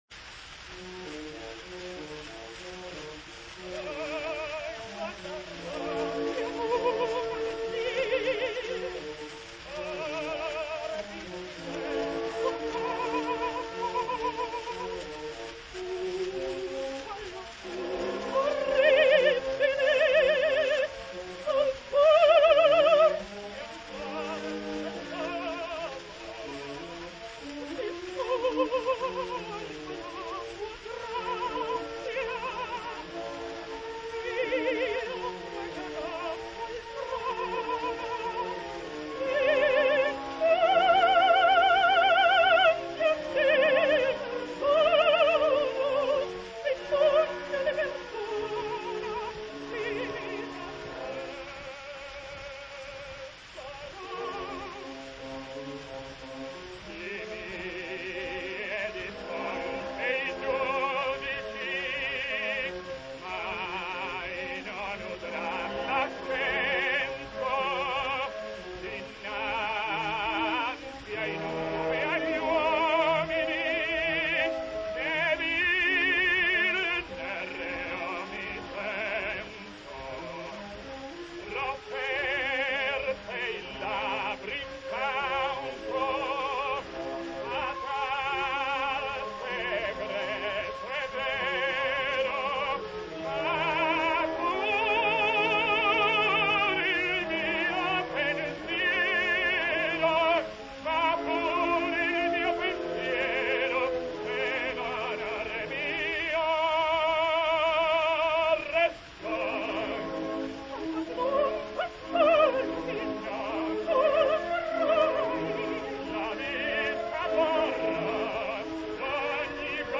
Maggiore impressione ancora è data dalla assoluta facilità con cui senza intaccare la qualità del suono, la penetrazione e lo squillo  la Arangi Lombardi esegue i passi più roventi della scrittura, da autentico soprano drammatico di Aida anche se la fraseggiatrice (parola grossa forse per l’Arangi Lombardi) predilige sempre l’espressione nostalgica ed intima.